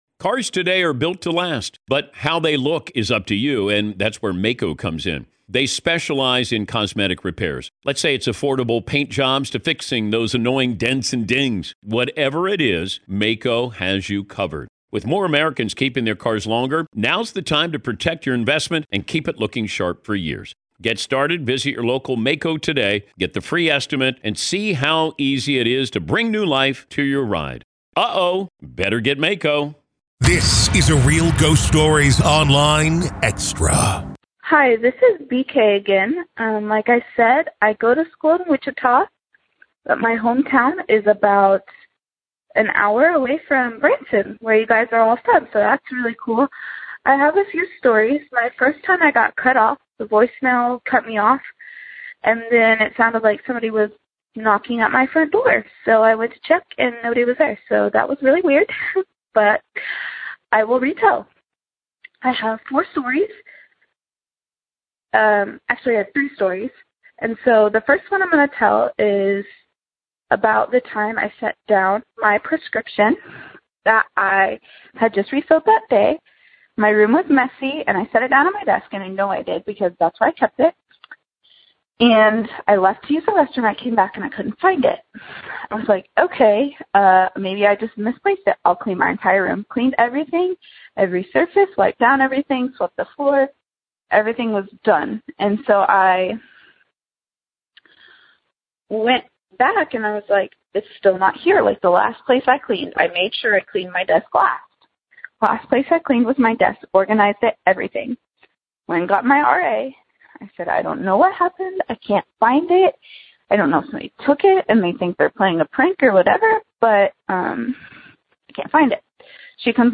What do you do when your prescription bottle disappears, you hear crying from an empty room, and a shadowy figure stands watch in your closet? In today's episode, one college student recounts her experiences with ghosts that seem to love playing pranks—whether it's sprinting down dorm halls at 3 A.M. or casually making her belongings vanish into thin air.